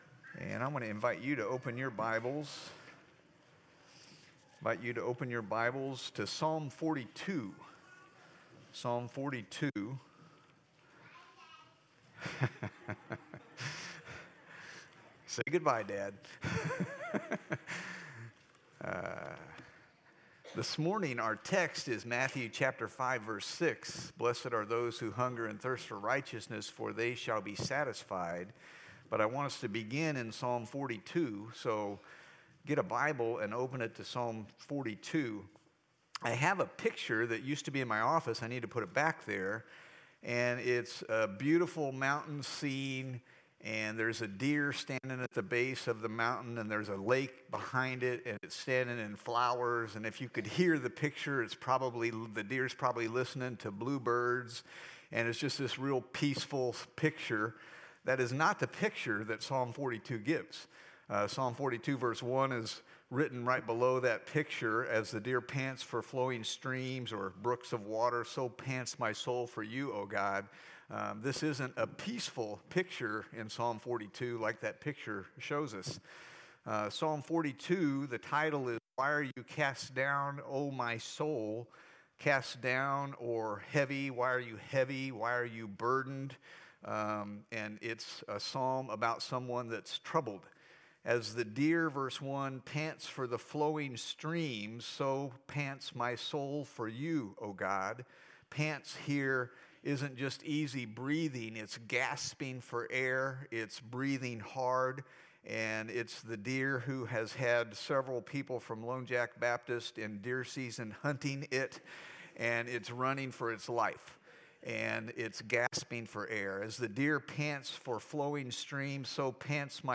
Sermons | Lone Jack Baptist Church